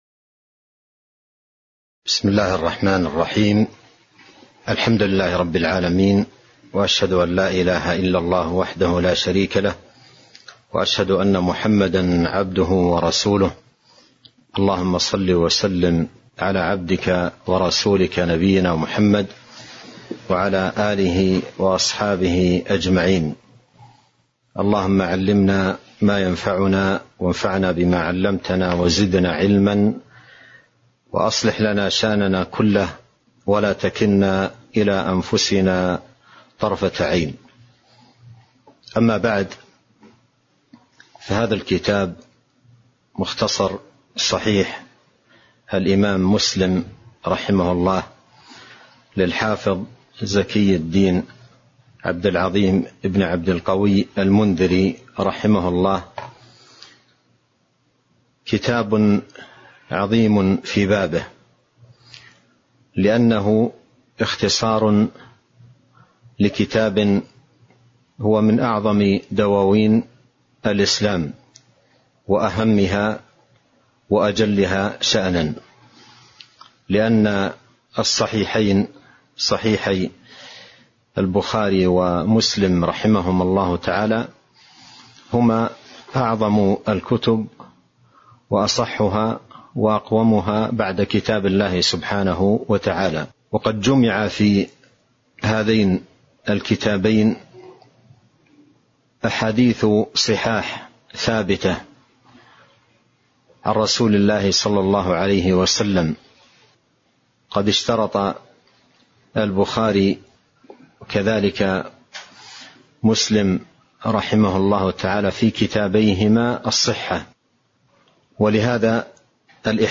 تاريخ النشر ٣ صفر ١٤٤٢ هـ المكان: المسجد النبوي الشيخ